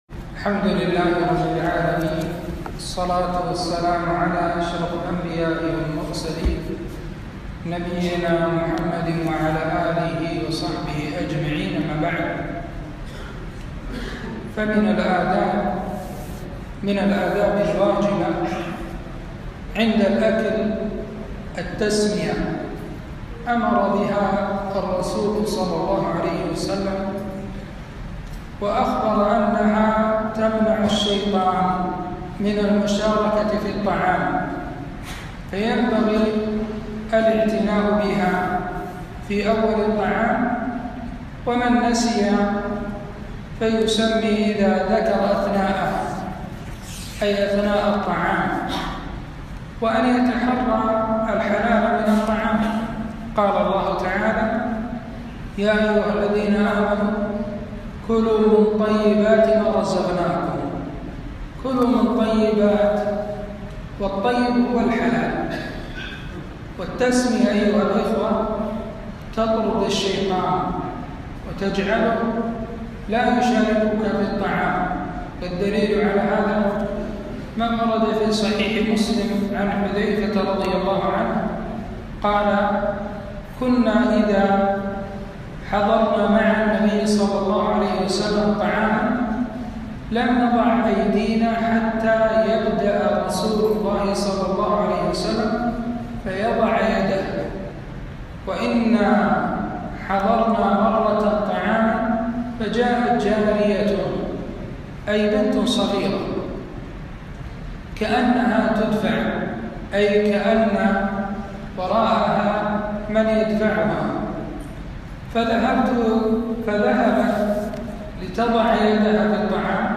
كلمة - من آداب الطعام